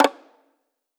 Perc  (2).wav